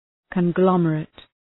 Shkrimi fonetik{kən’glɒmə,reıt}